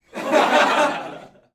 ShortStockLaughter1
adults audience chuckle fun funny haha laugh laughing sound effect free sound royalty free Funny